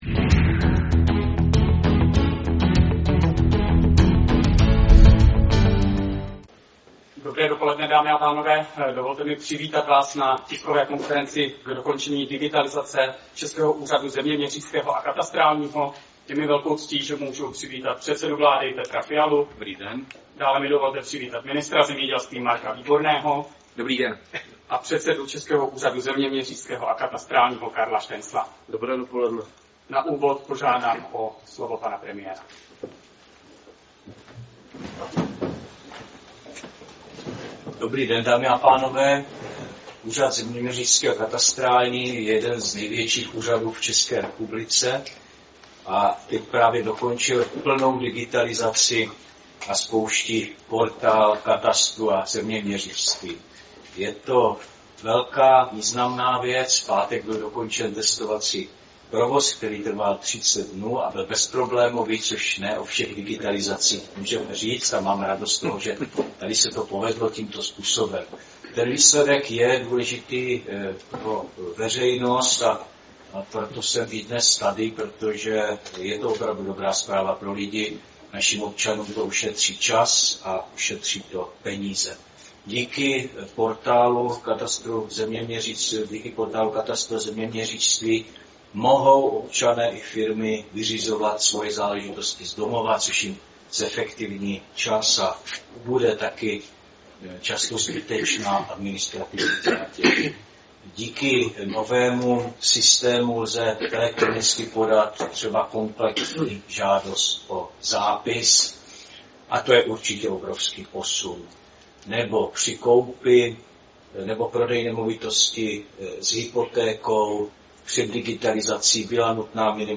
Tisková konference - TV Zemědělec – internetová televize